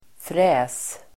Uttal: [frä:s]